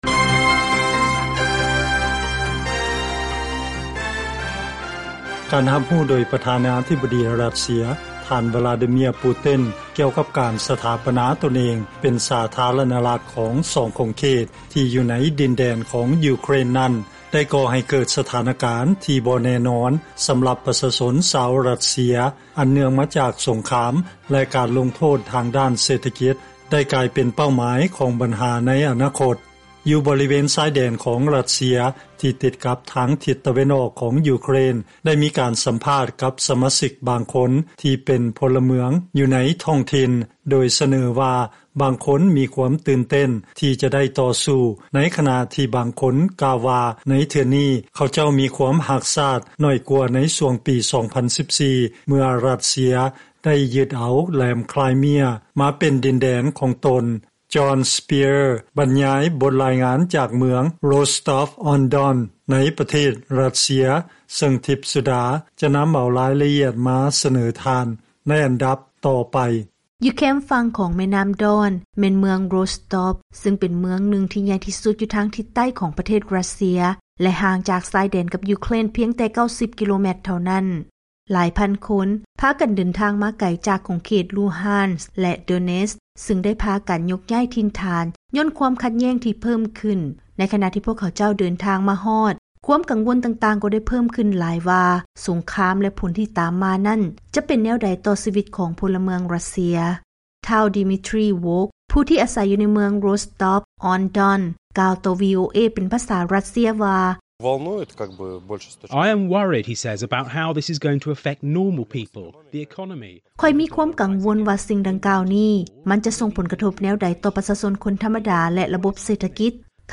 ເຊີນຟັງລາຍງານກ່ຽວກັບ ຄວາມຄິດເຫັນຂອງປະຊາຊົນຣັດເຊຍ ຕໍ່ຄວາມຂັດແຍ້ງຢູ່ໃນຂົງເຂດຊາຍແດນຂອງຢູເຄຣນ